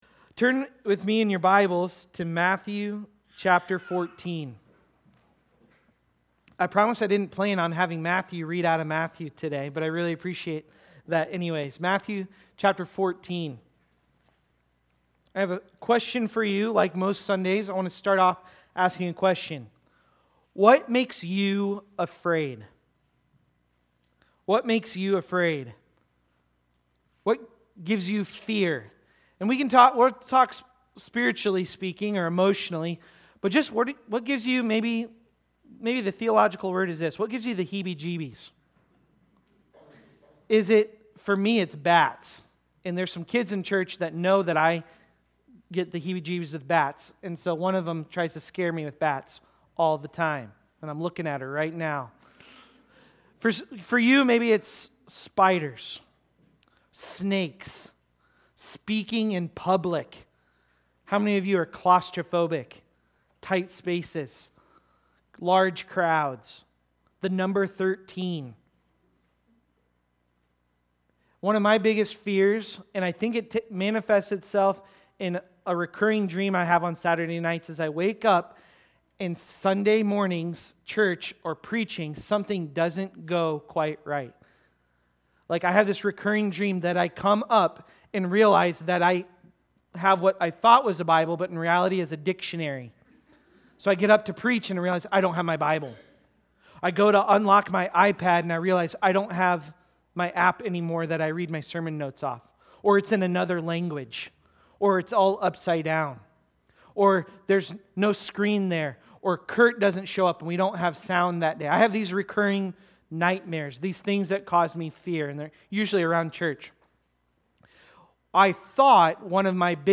Walking-and-Wavering-Faith-Sermon-Audio.mp3